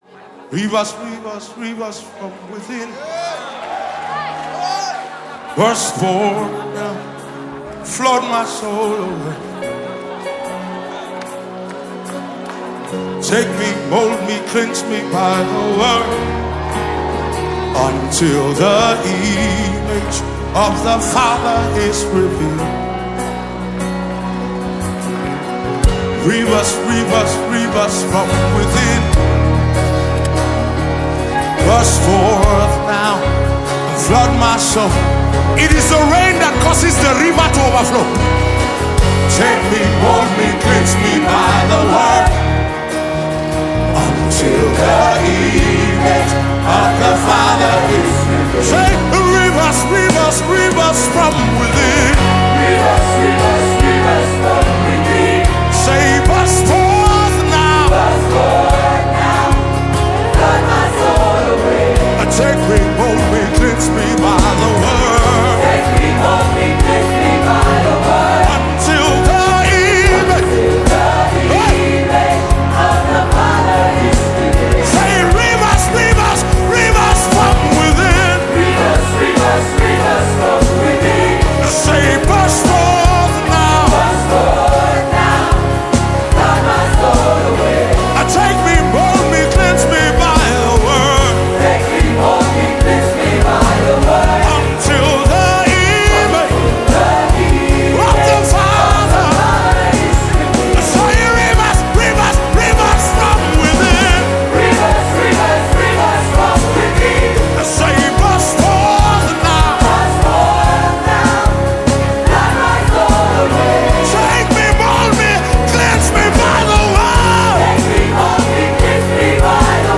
Nigeria Gospel Music
Contemporary Christian music singer